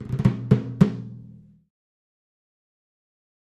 Timpani, (Hands), Short Battle Accent, Type 1